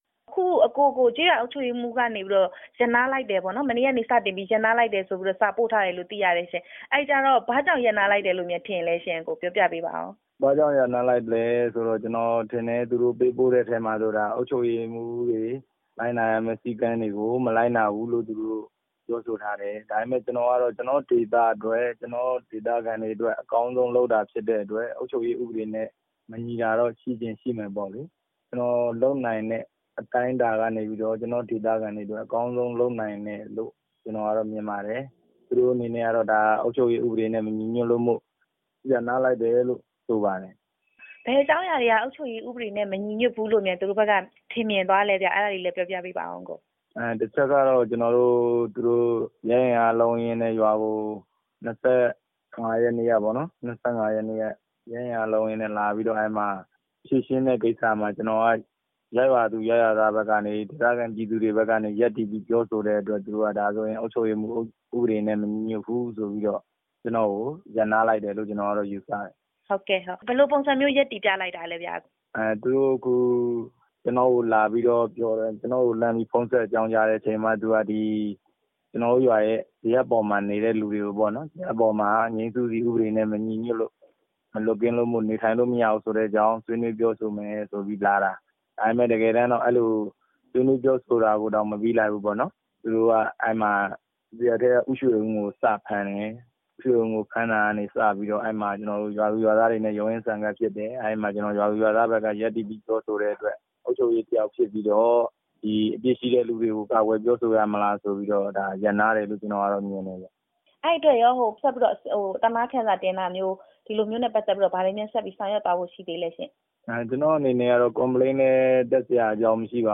တာဝန်ရပ်ဆိုင်းခံရတဲ့ ကျေးရွာအုပ်ချုပ်ရေးမှူးနဲ့ မေးမြန်းချက်